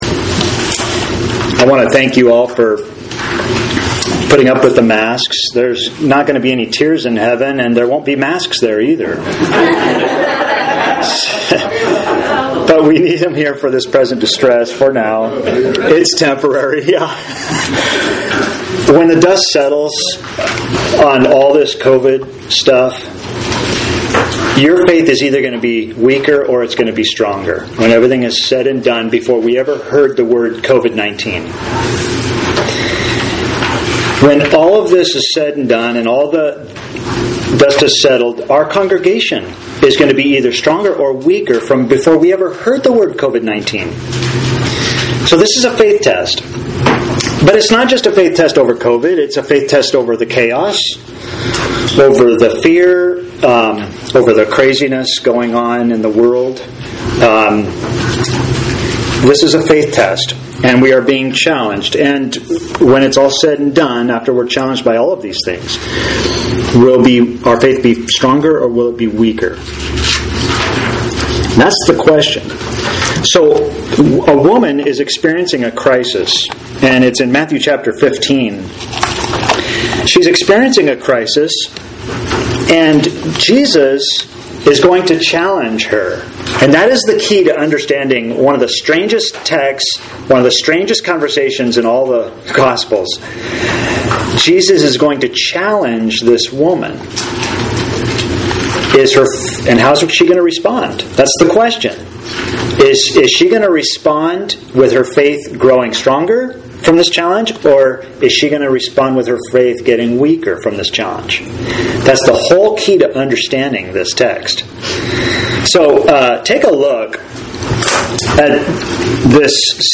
Sermon for Sunday August 30, 2020 – AUDIO | TEXT PDF
miracles22live.mp3